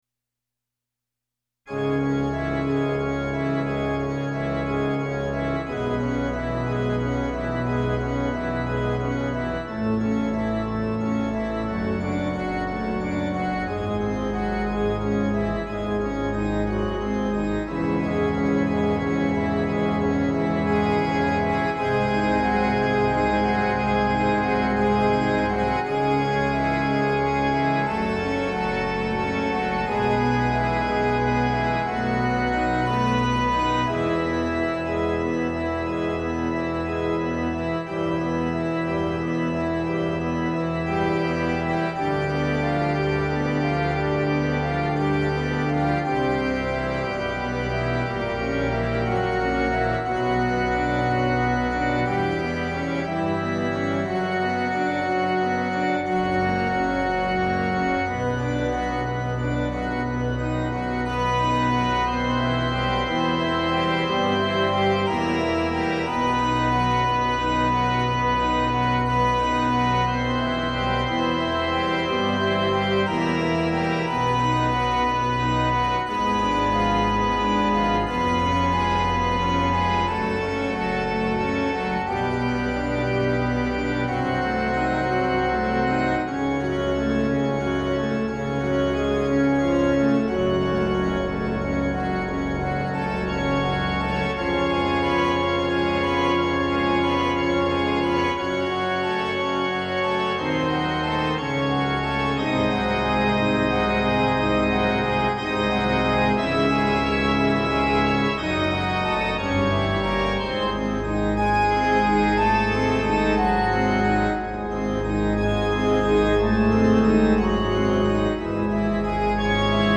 関連MIDIデータ：
ベートーベン作曲ピアノソナタOp.27の２『月光』第一楽章(オルガン音)